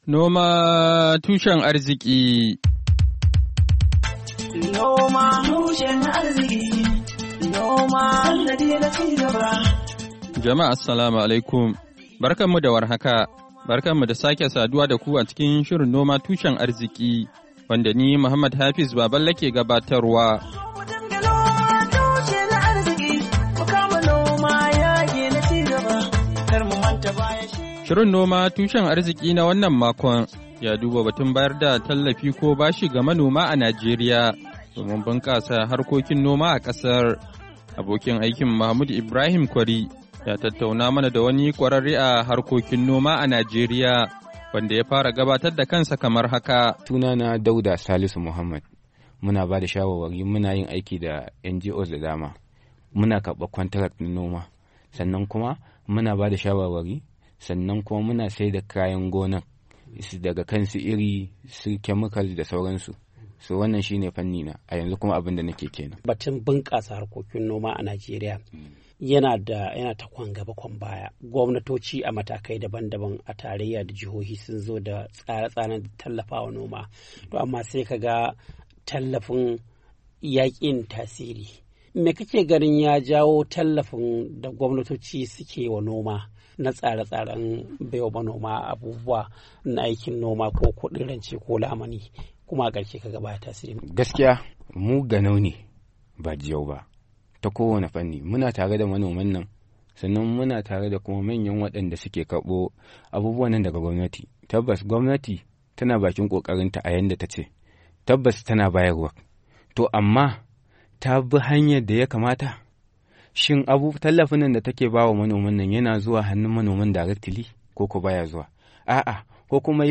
NOMA TUSHEN ARZIKI: Hira Da Kwararre Kan Harkokin Noma A Kan Batun Bunkasa Noma A Najeriya - Kashin Na Daya - Afrilu 18, 2023